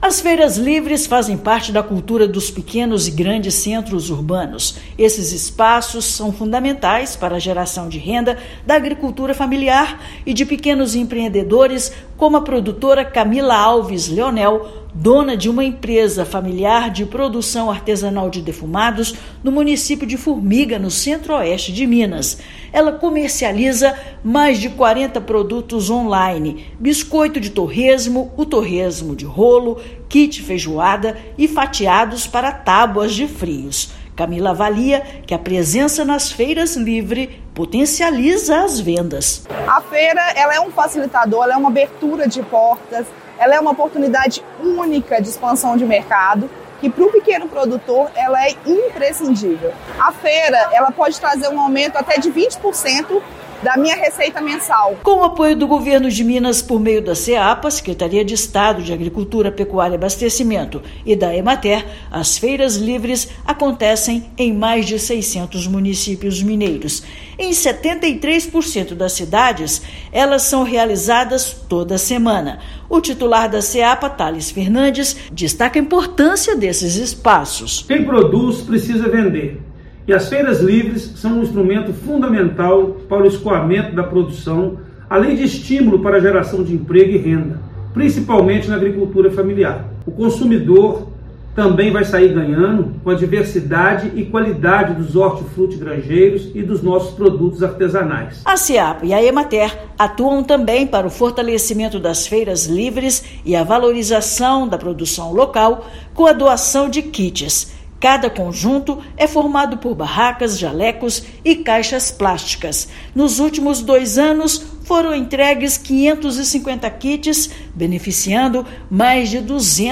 Com o apoio do Governo do Estado, por meio da Secretaria de Agricultura e da Emater-MG, mostras estão presentes em mais de 600 municípios. Ouça a matéria de rádio.